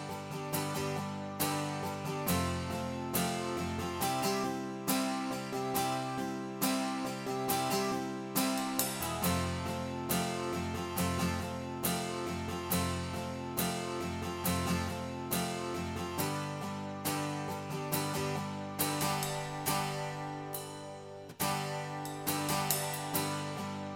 No Piano Pop (1970s) 5:42 Buy £1.50